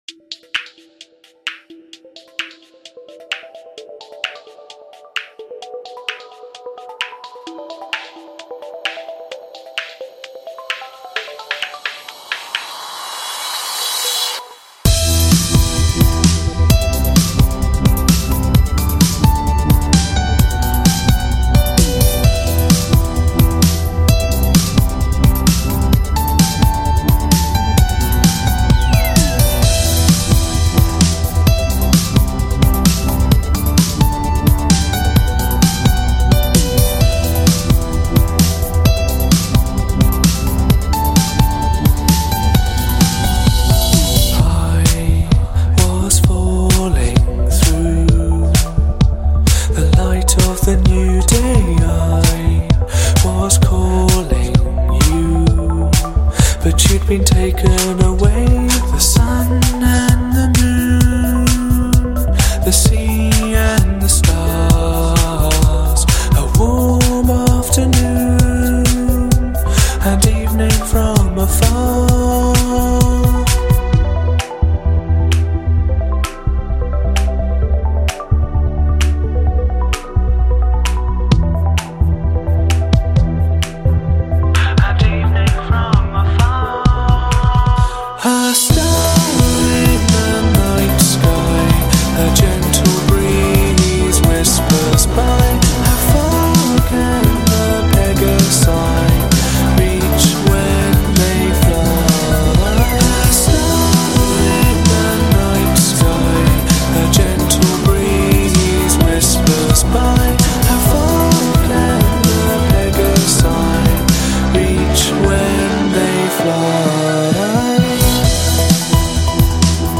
- This is me singing, yes.